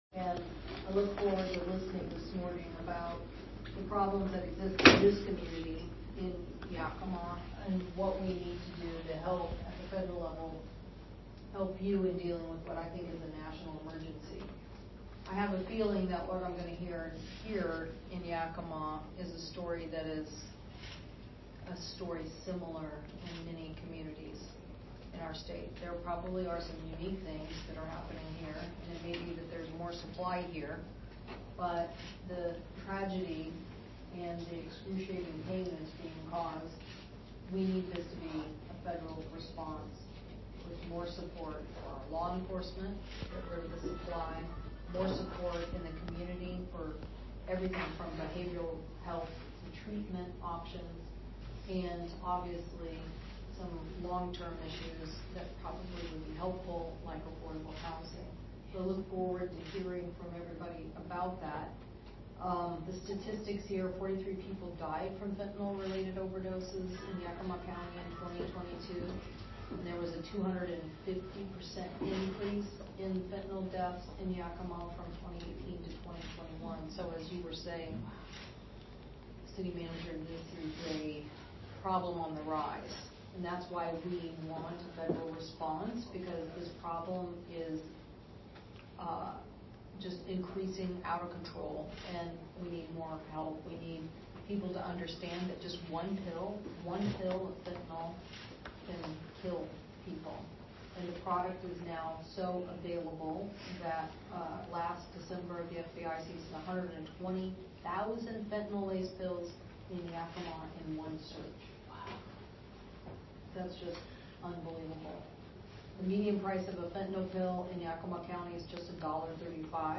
Cantwell Convenes Fentanyl Roundtable in Yakima
YAKIMA, WA – Today, U.S. Senator Maria Cantwell (D-WA) heard from first responders, health care providers, law enforcement, and members of the community who have been personally impacted by fentanyl during a roundtable discussion in Yakima at Triumph Treatment Services.